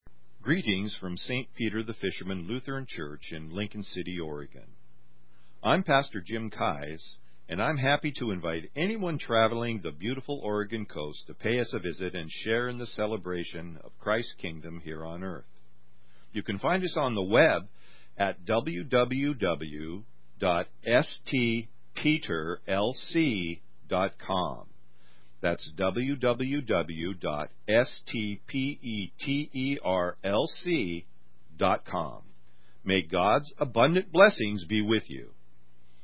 Greetings from Lincoln City Oregon.